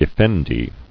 [ef·fen·di]